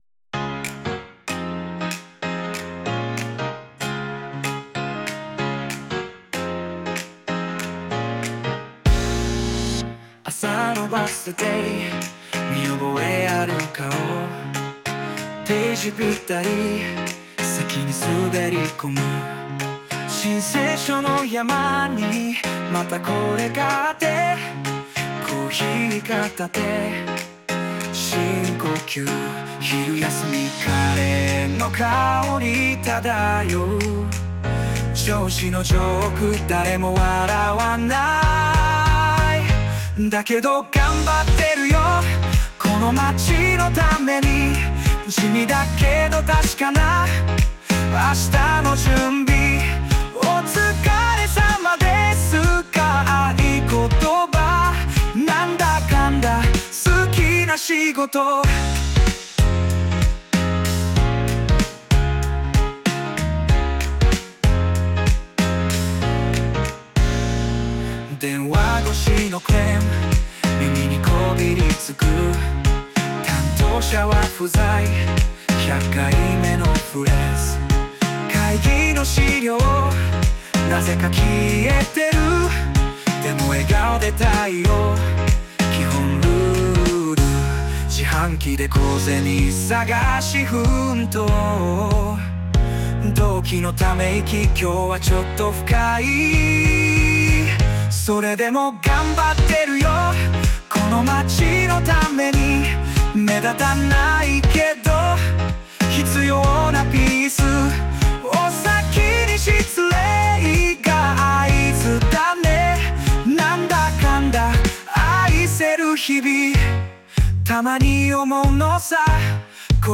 著作権フリーオリジナルBGMです。
男性ボーカル（邦楽・日本語）曲です。